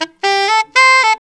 SAX JN LIC01.wav